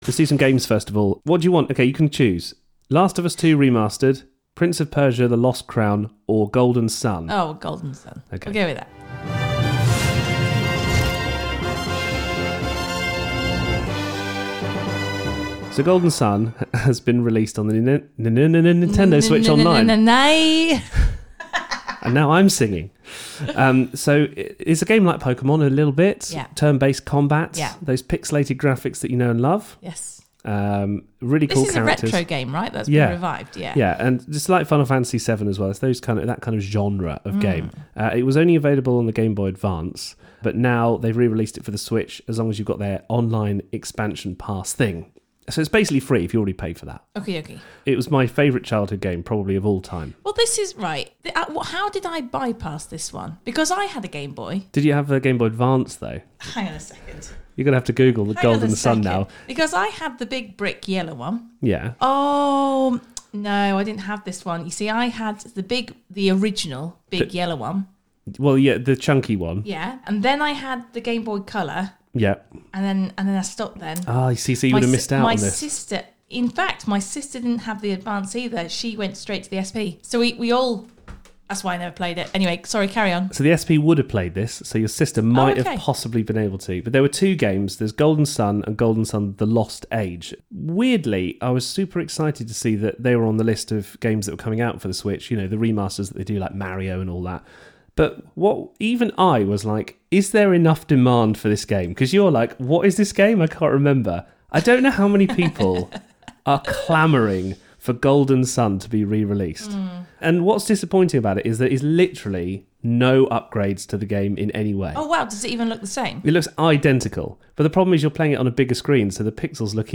Review: Golden Sun, Golden Sun: The Lost Age